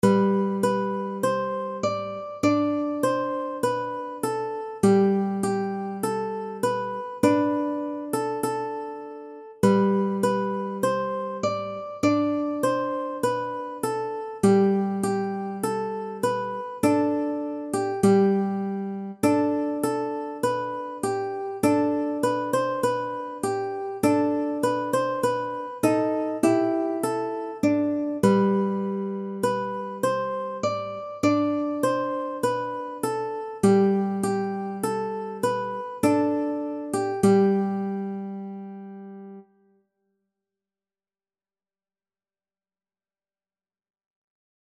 クラシック
アップした楽譜は原曲の特徴や魅力を損なわず、ギター入門者の方向けにギターソロで弾けるようにアレンジしました。
PC演奏（楽譜をそのままMP3にエクスポート）ですが